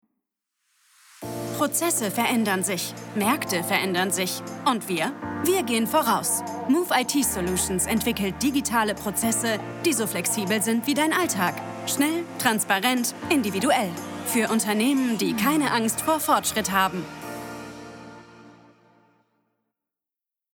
Young, Natural, Playful, Accessible, Friendly
Corporate